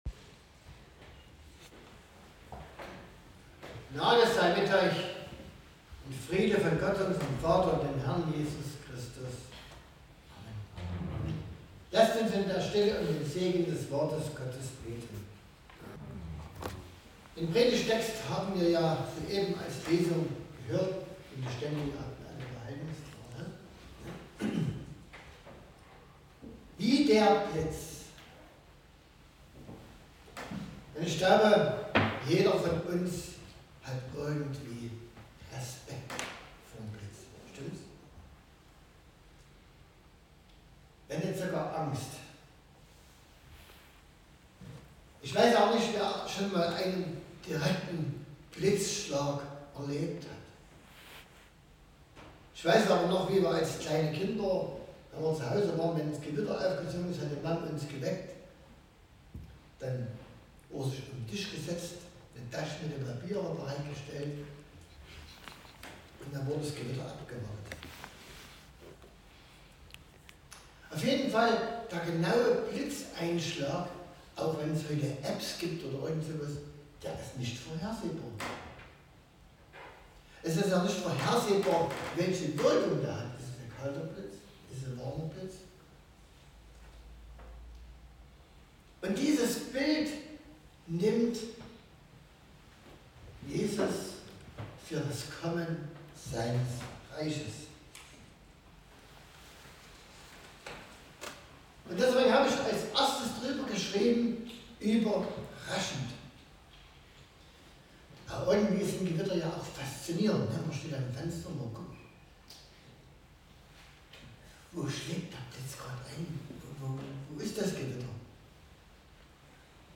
Eine Predigt über das Wiederkommen JESU.
20-30 Gottesdienstart: Predigtgottesdienst Wildenau Eine Predigt über das Wiederkommen JESU.